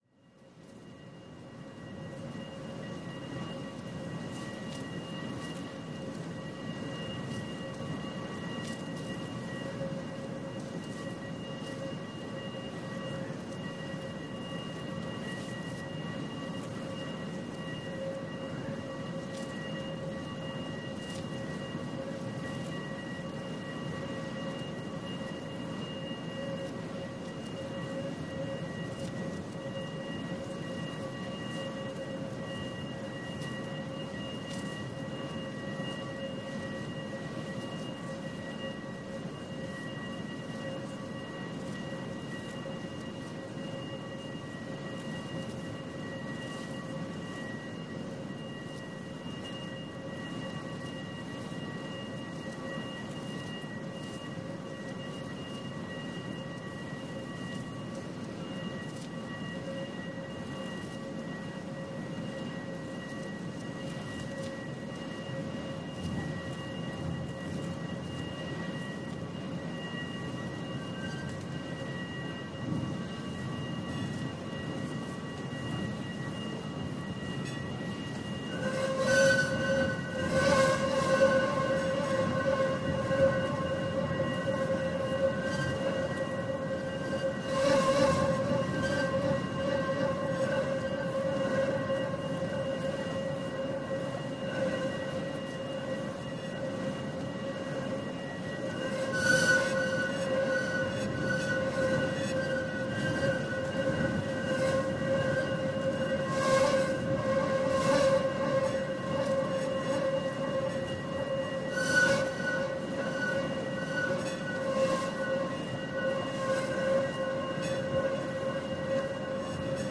A streetcar journey in sound